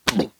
hurt_1.wav